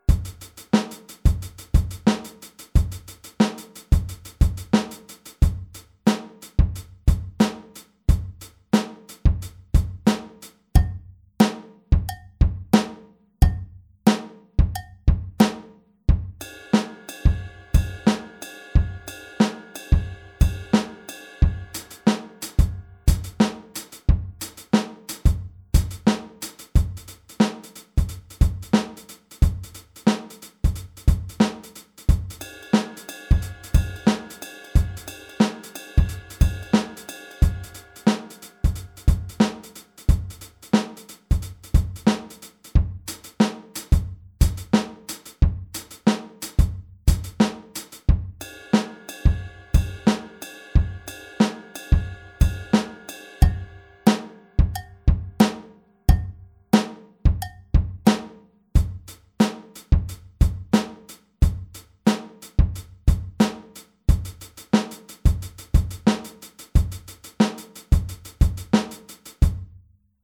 Wenn du nun endlich hier angekommen bist, dann wäre der nächste Schritt alle 7 Varianten zu einem großen Ganzen zu verbinden, zum Beispiel so (zu hören sind immer 2 Takte, es sollten aber mindestens 4 sein):